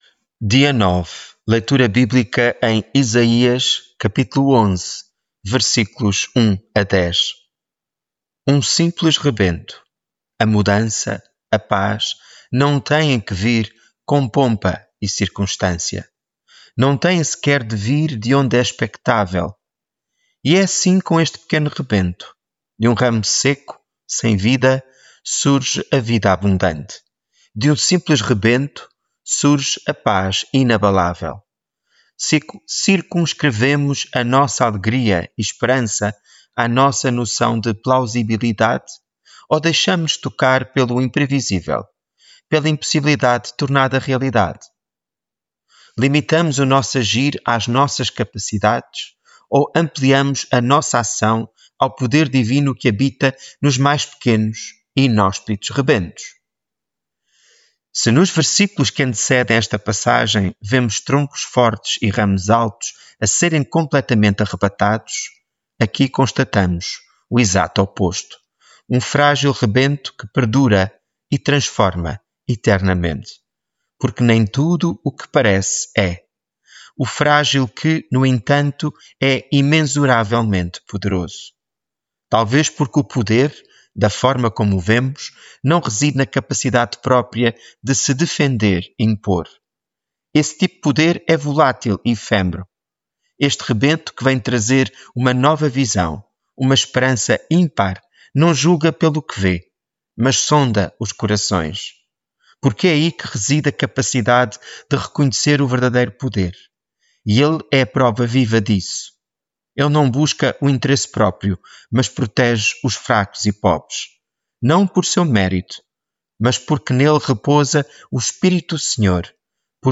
Devocional
Leitura bíblica em Isaías 11:1-10